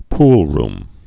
(plrm, -rm)